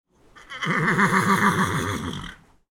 Horse Whinny, Close, A
Category 🐾 Animals